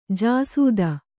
♪ jāsūda